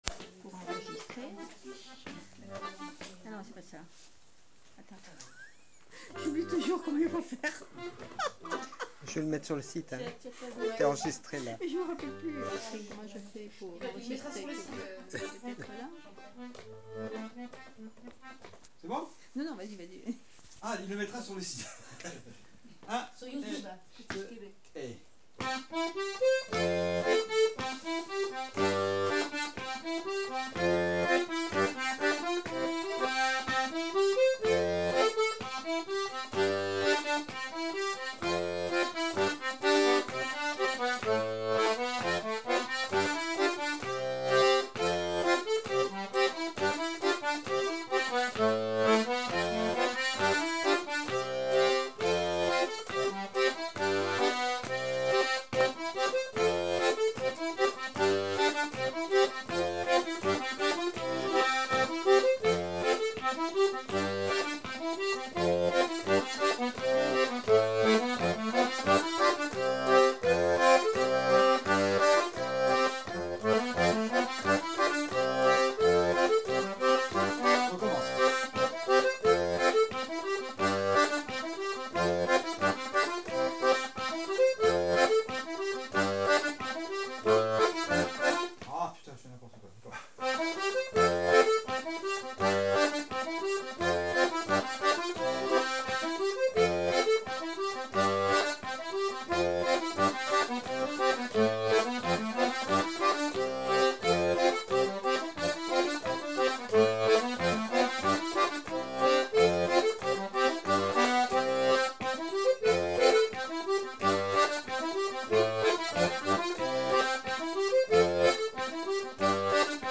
l'atelier d'accordéon diatonique
enregistrement live